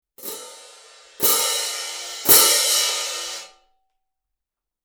Sabian 16" AA Marching Band Cymbals
Medium-heavy weight provides full-bodied sound with long decay.
Hand Cymbals.
Loud Crash Build